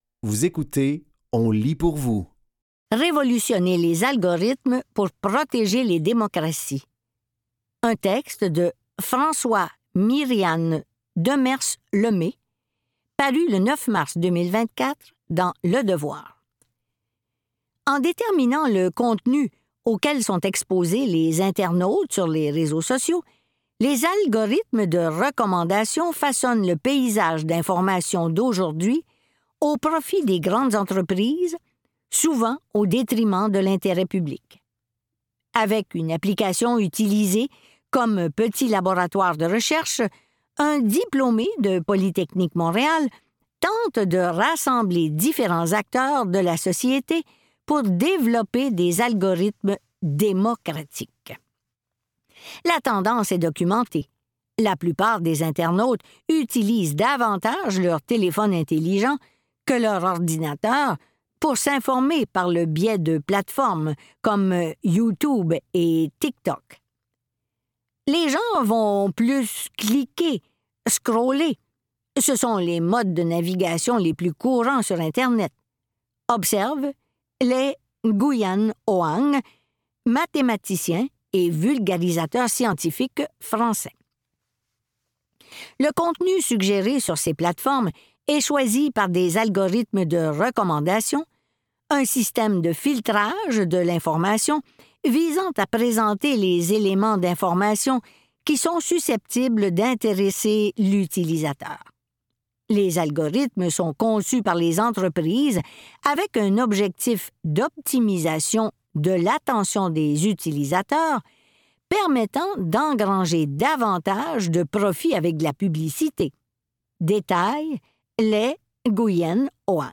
Dans cet épisode de On lit pour vous, nous vous offrons une sélection de textes tirés des médias suivants: Le Devoir et La Presse.